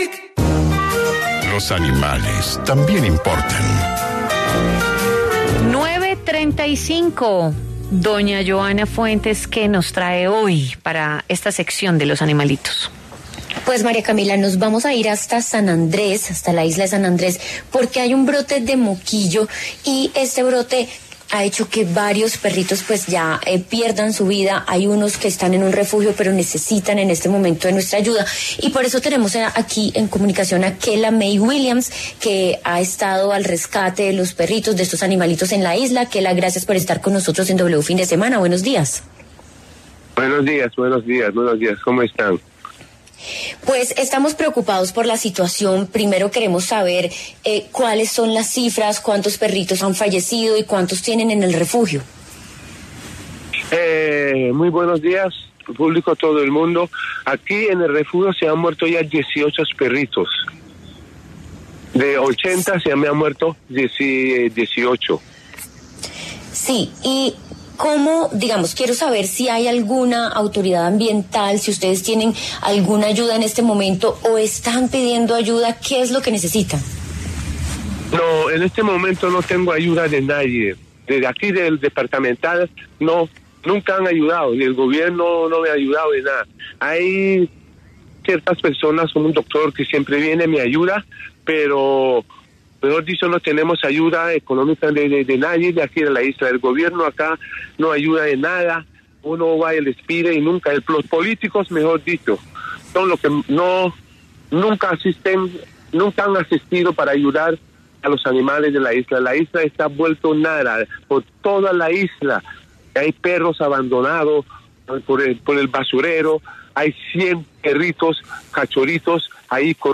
En diálogo con W Fin de Semana, el rescatista de animales